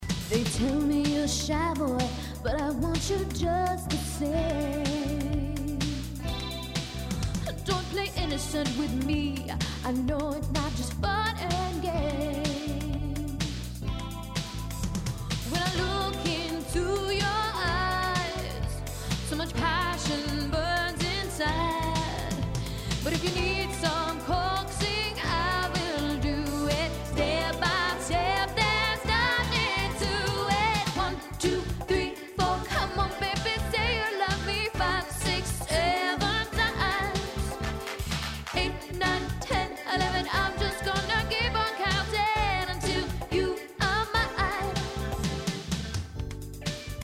P  O  P     and    R  O  C  K      C  O  V  E  R  S